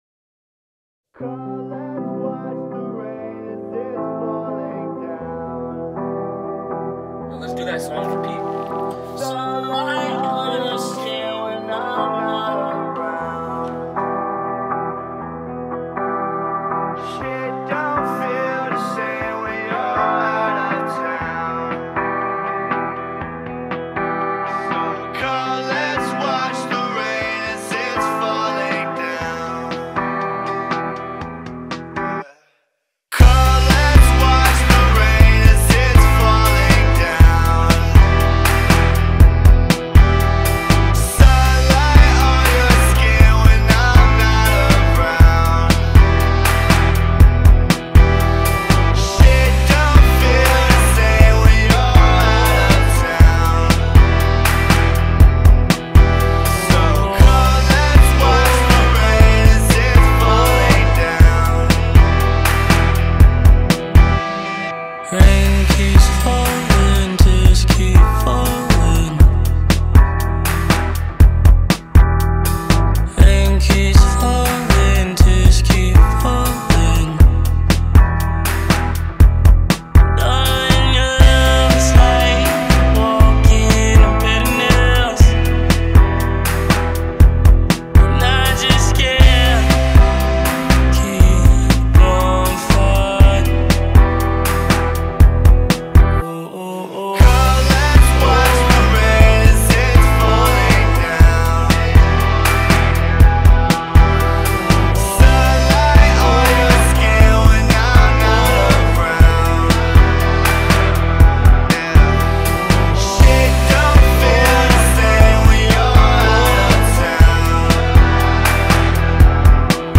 غمگین
غمگین خارجی